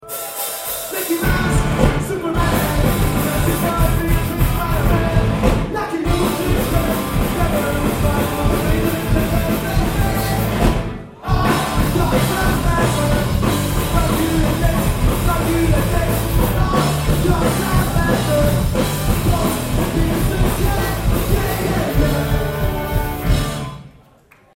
Aufnahmegerät: Sharp IM-DR420H (Mono-Modus)
Mikrofon: Sony ECM-T6 (Mono)
Gute Qualität, manchmal hört man zu laut Leute mitsingen.